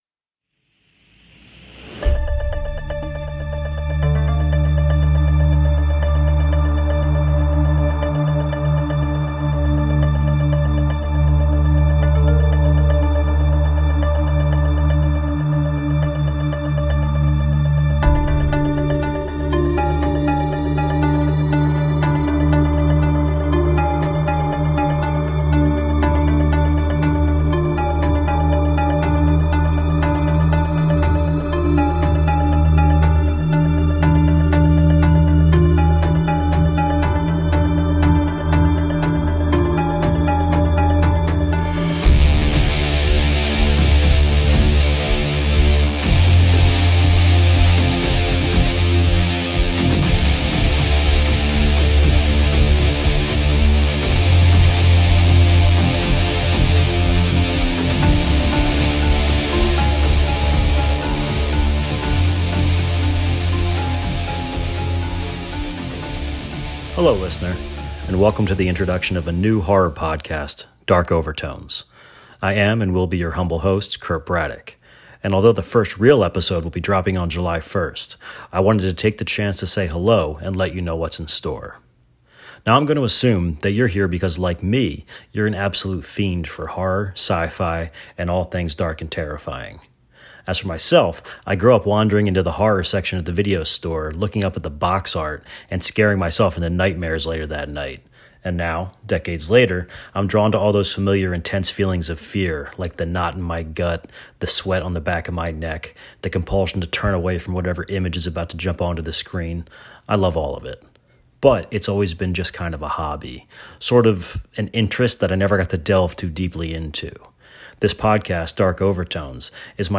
Dark Overtones features discussions about the themes in the horror movies that terrify us, featuring conversations with experts who specialize in the topics that drive some of the best horror films of the last 50 years.
Trailer: